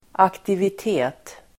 Uttal: [aktivit'e:t]